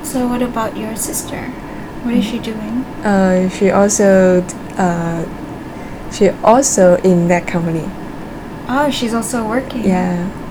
S1 = Brunei female S2 = Chinese female Context: S1 is asking S2 about her family.
S2 : yeah Intended Words: also in that Heard as: owns an internet Discussion: The spurious [t] sound after the first also may have confused S1.